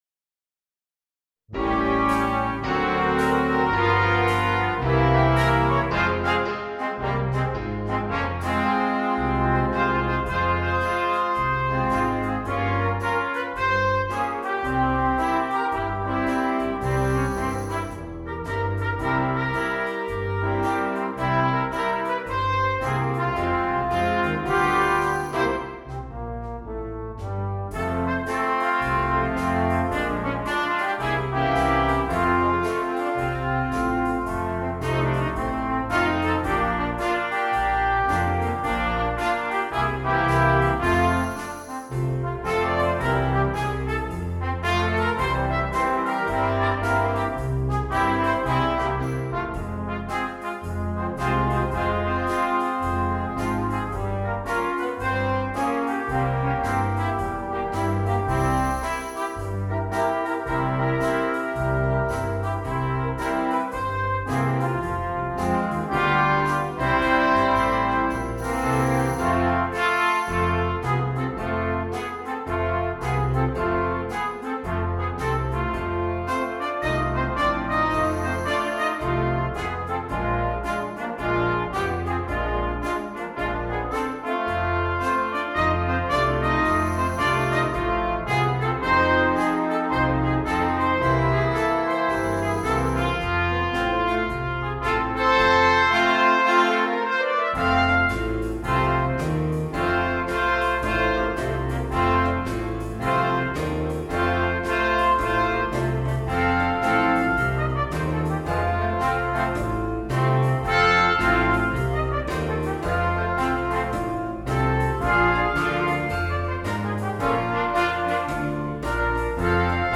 Gattung: für 4 - 8-stimmiges gemischtes Ensemble
Besetzung: Ensemble gemischt